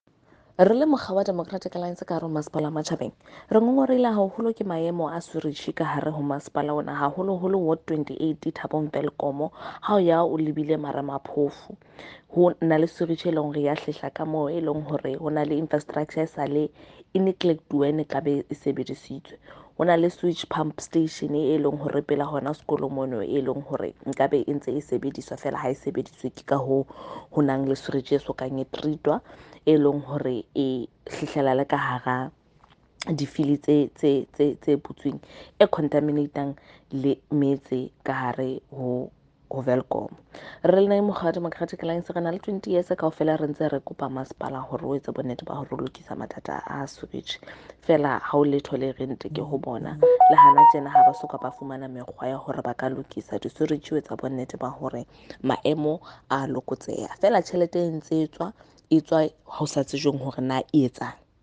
Sesotho by Karabo Khakhau MP.
Sotho-voice-Karabo-3.mp3